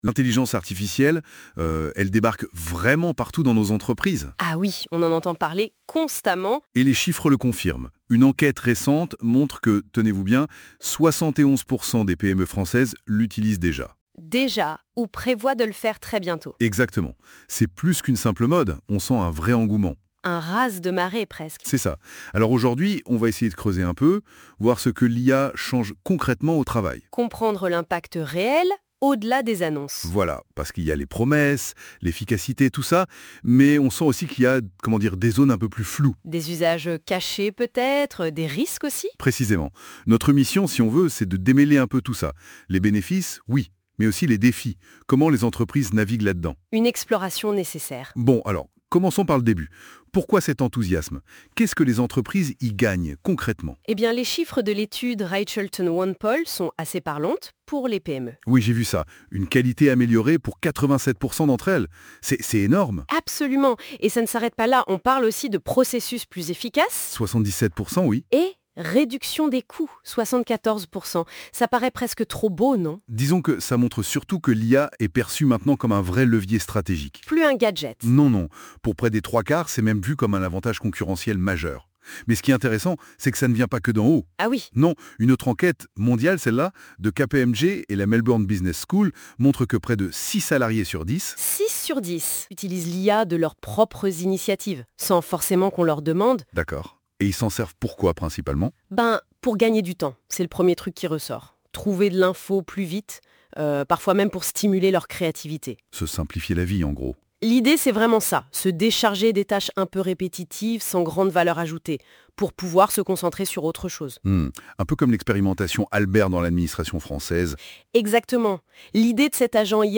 [!Success]Ecoutez la conversation !